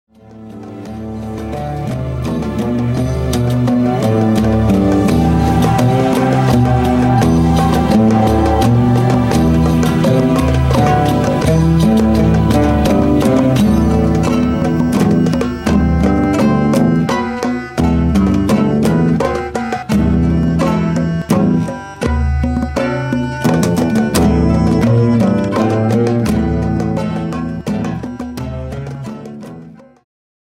ジャンル Progressive
シンフォニック系
ワールドミュージック
民族楽器とシンフォニック・ロックが絶妙に絡み合い異郷に誘う！
oud
acoustic guitar
tombak